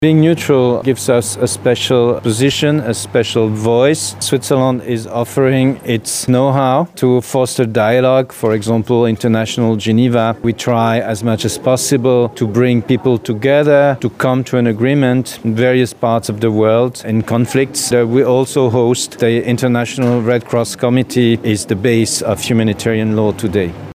O historycznych i współczesnych wyzwaniach dla Szwajcarii, a także o tradycji humanitaryzmu mówił w Lublinie ambasador tego kraju Fabrice Filliez.
Okazją do tego było kolejne spotkanie z cyklu „Rozmowy Dyplomatyczne” na Wydziale Politologii i Dziennikarstwa UMCS w Lublinie.